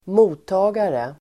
Uttal: [²m'o:ta:gare]